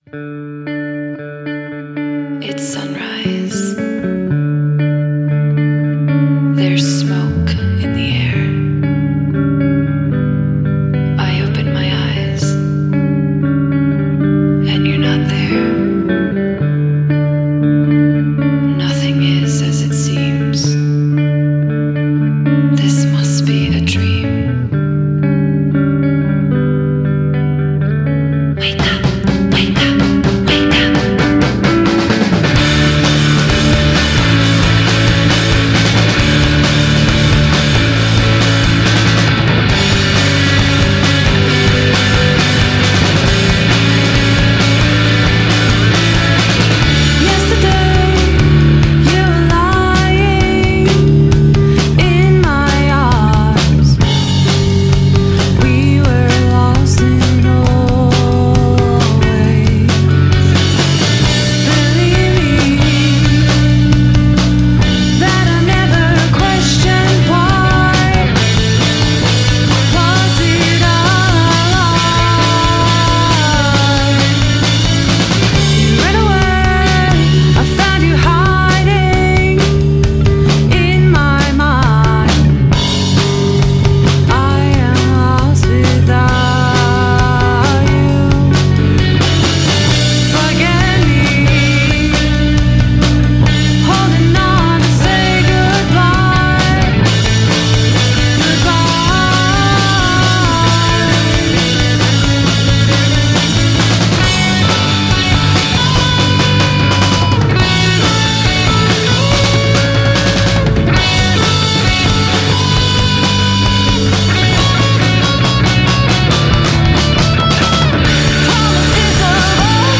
female-fronted rock band
melodious, poppy hooks
arpeggiated, frenetic ballads